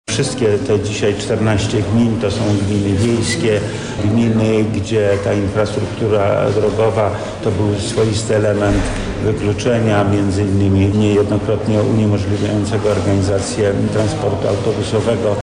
• mówi wojewoda lubelski Lech Sprawka.